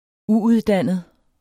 Udtale [ ˈuuðˌdanˀəð ]